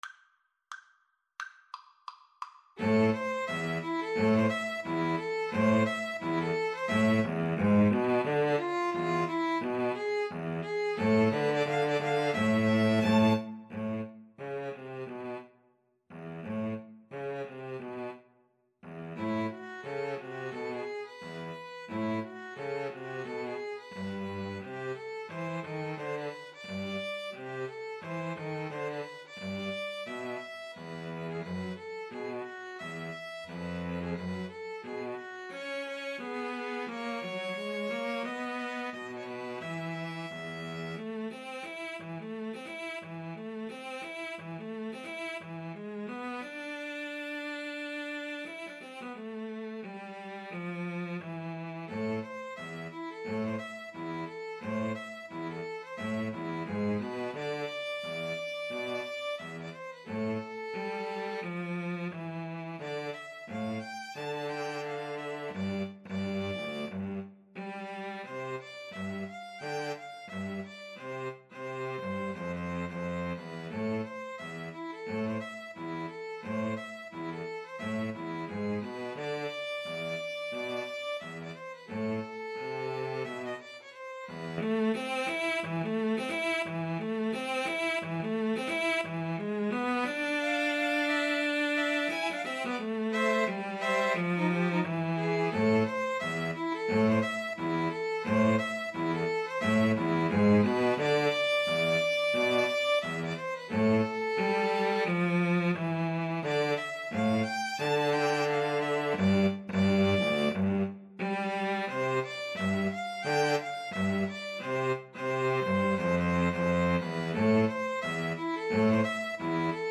~ = 176 Moderato
Jazz (View more Jazz Piano Trio Music)